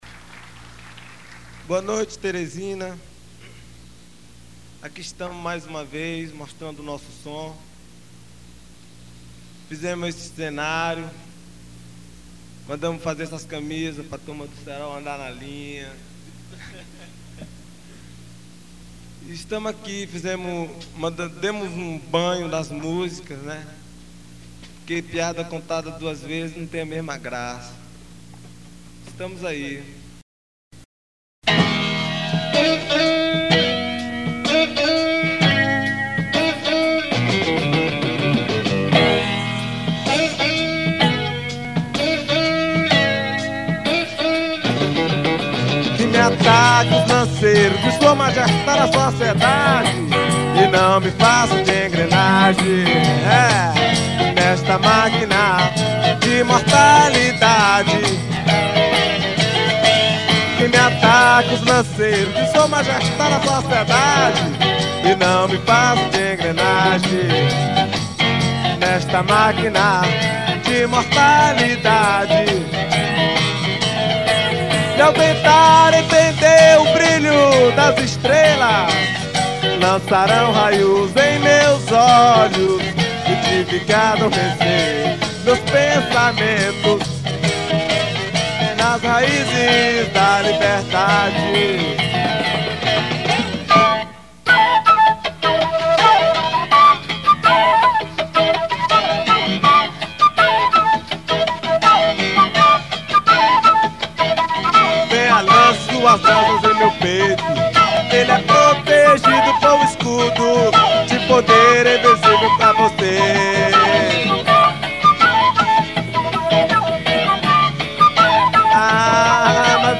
973   06:05:00   Faixa:     Rock Nacional